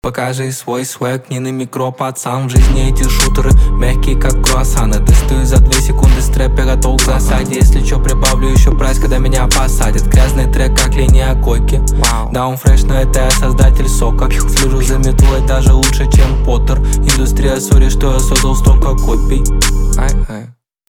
русский рэп , битовые , басы , пацанские , жесткие